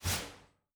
Swing sword_3.wav